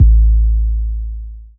SS_808_3.wav